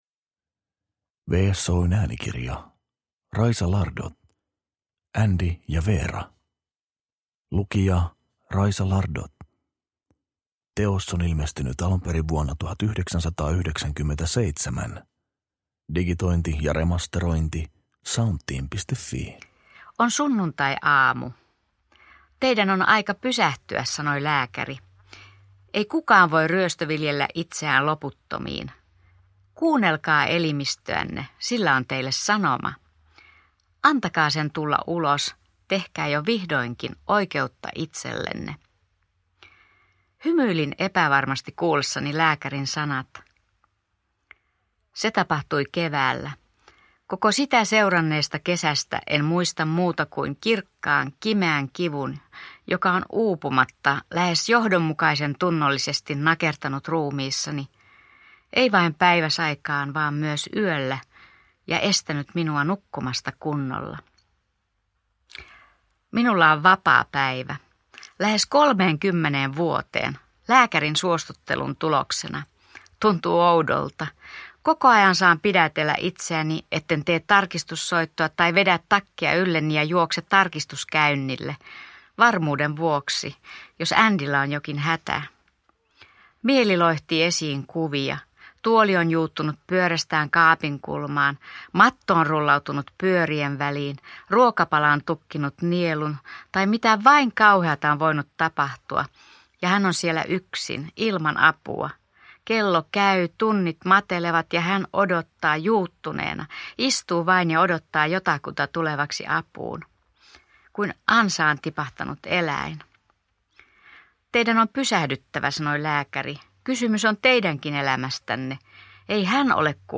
Andy ja Vera – Ljudbok